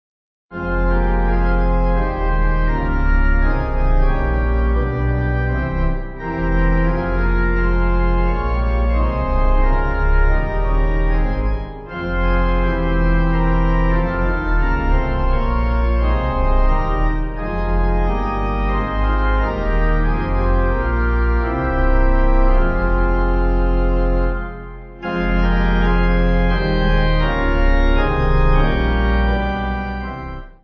(CM)   4/Fm